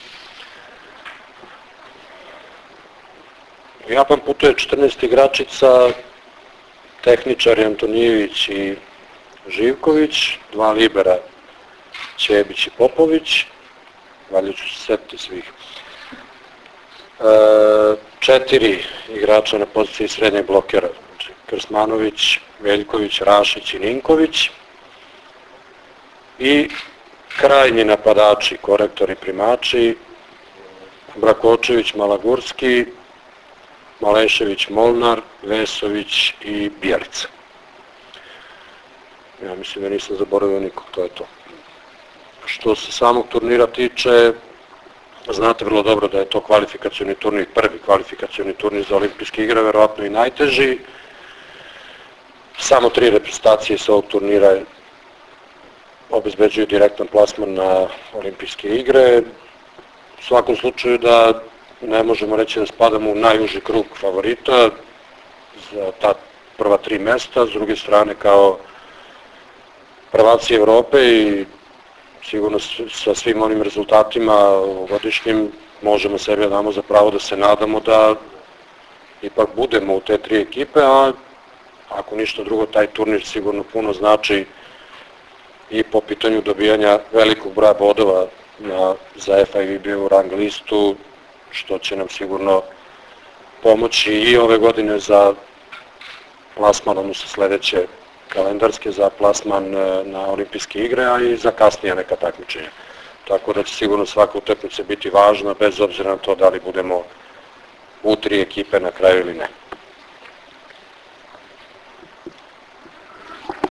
Tim povodom danas je u beogradskom hotelu “M” održana konferencija za novinare
IZJAVA